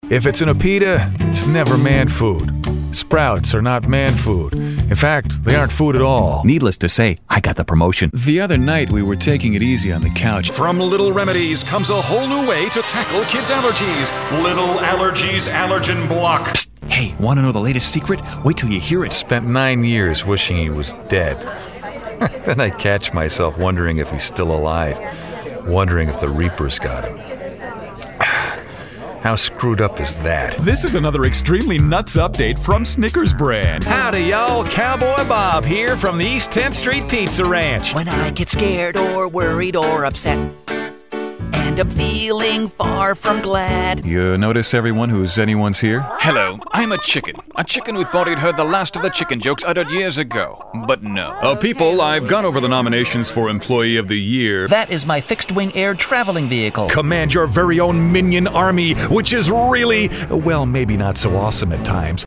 My studio is fully equipped, including ISDN and phone patch for remote recording and direction.
Voice overs, US English, narrations, ISDN, promo, imaging, mid, young, middle, dark, brightly, middle west, mid-Atlantic
Sprechprobe: Sonstiges (Muttersprache):